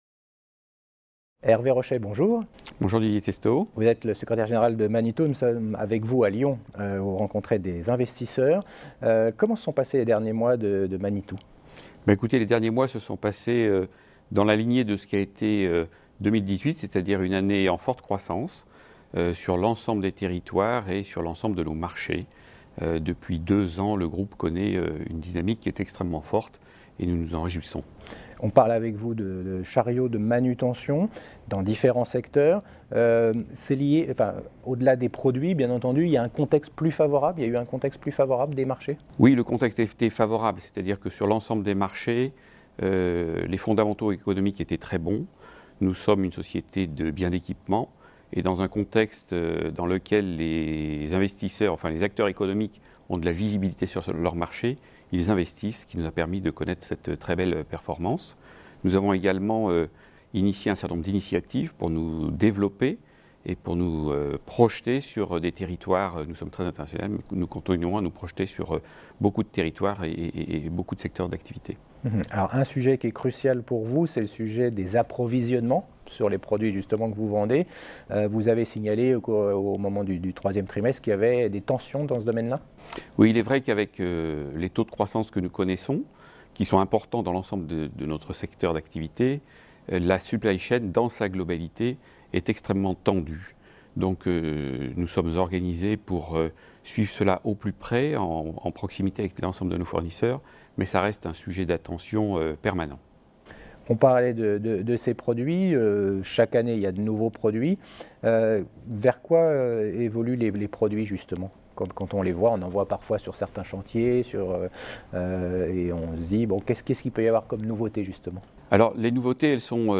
La Web Tv a rencontré les dirigeants au Oddo Forum qui s’est tenu à Lyon le 10 et le 11 janvier.
Vous vous intéressez à la société Manitou, retrouvez toutes les interviews déjà diffusées sur la Web TV via ce lien : Vidéos Manitou.